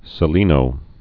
(sĭ-lēnō)